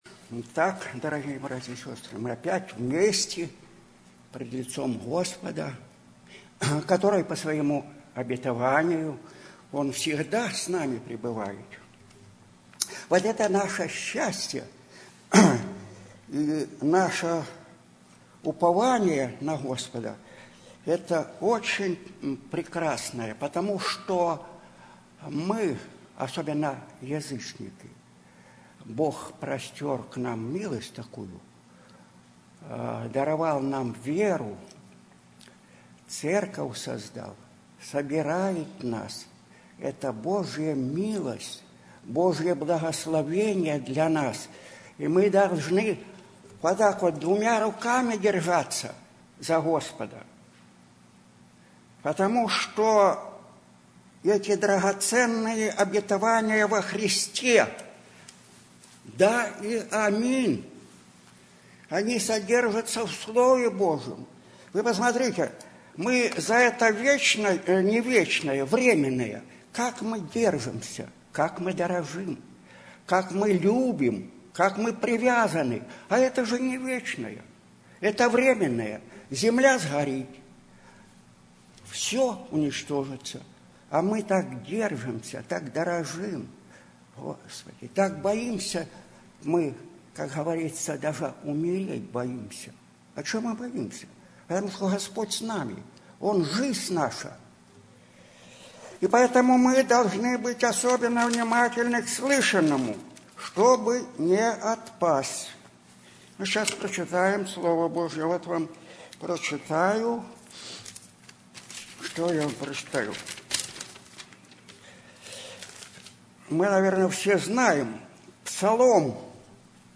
Sermons 08/2010 :: Audio & Video :: Slavic Evangelical Baptist Church of Hollywood